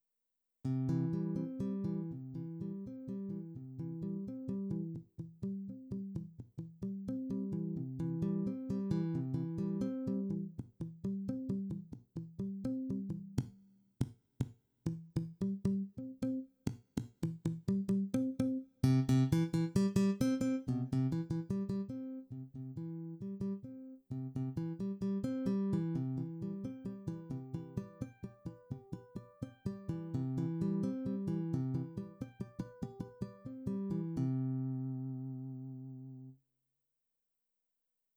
Edit2: au cas ou ce n'était pas clair, les enregistrement au dessus sont TOUS des patchs, pas de combi. Pas de multi timbralité dans aucun des dit enregistrement.